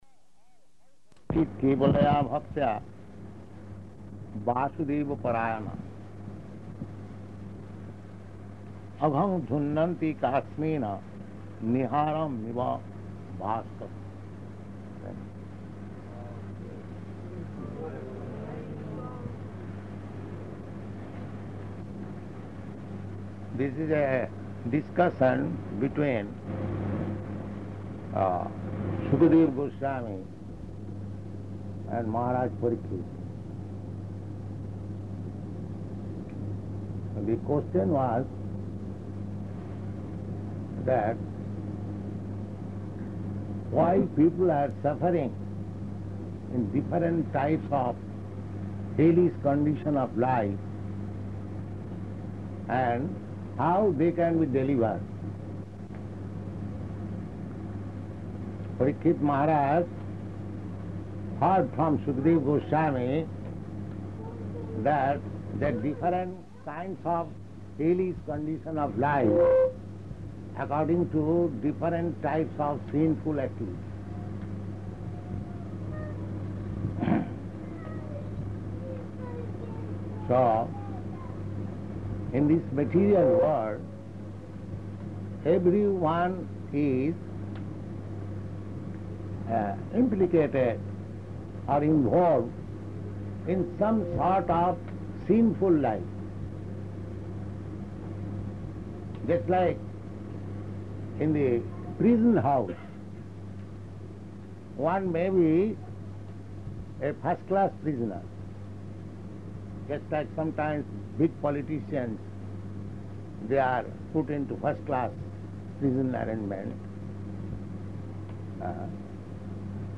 Type: Srimad-Bhagavatam
Location: London